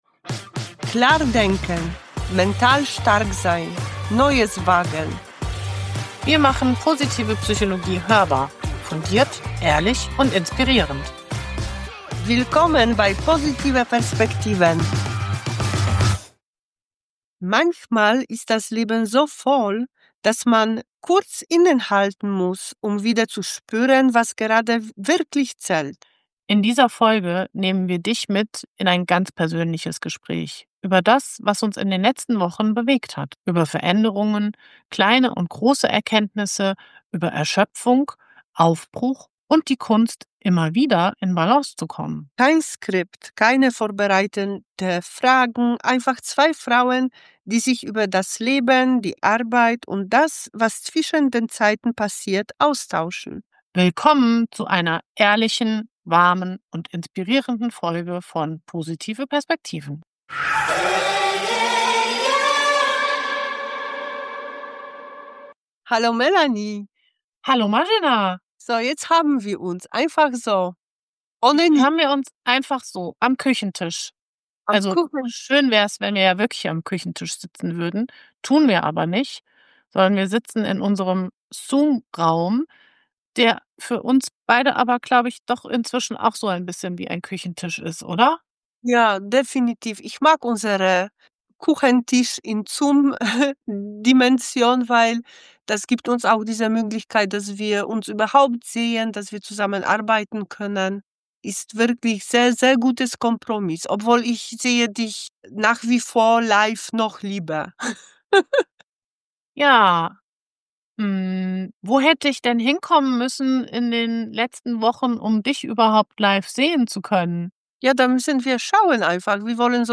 Manche der schönsten Gespräche entstehen am Küchentisch. In dieser Folge nehmen wir dich genau dorthin mit. Du wirst dich wundern, was bei dieser Unterhaltung alles zu Sprache kommt.